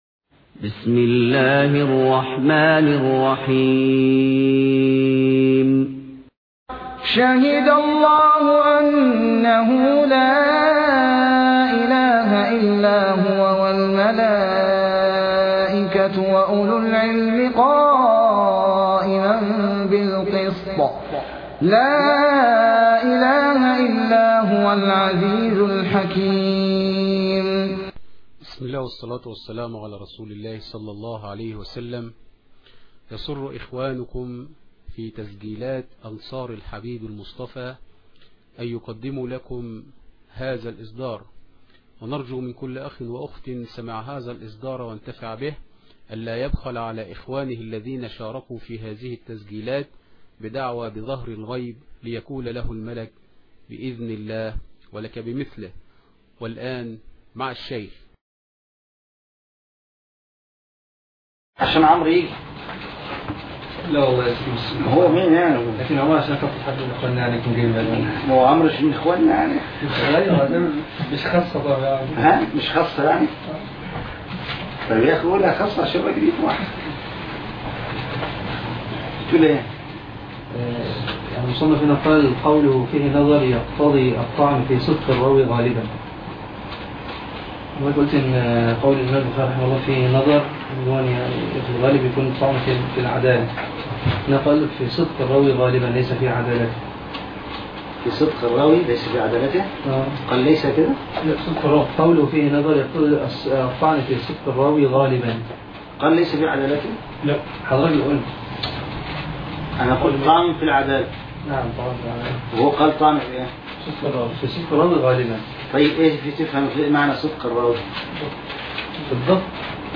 الدرس 44 ( موسوعه شروح المصطلح والعلل )